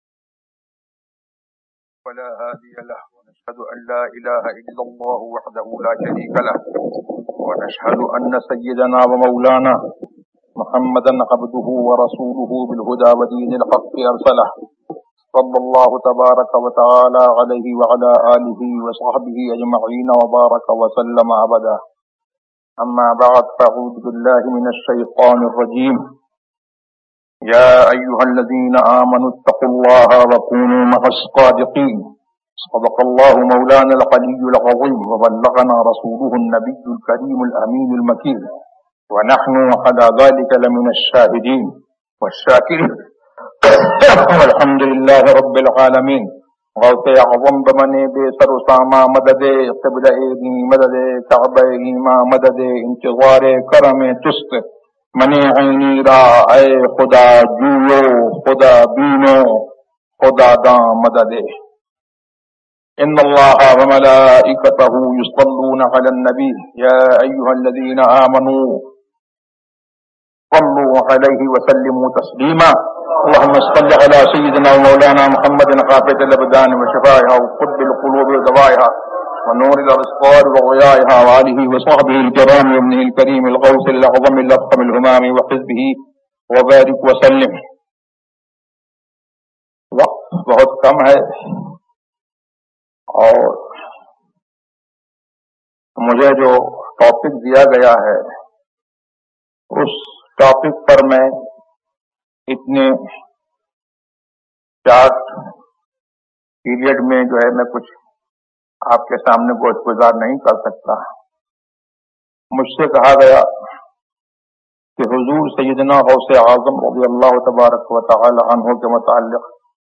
فیضان غوث اعظم ZiaeTaiba Audio میڈیا کی معلومات نام فیضان غوث اعظم موضوع تقاریر آواز تاج الشریعہ مفتی اختر رضا خان ازہری زبان اُردو کل نتائج 1070 قسم آڈیو ڈاؤن لوڈ MP 3 ڈاؤن لوڈ MP 4 متعلقہ تجویزوآراء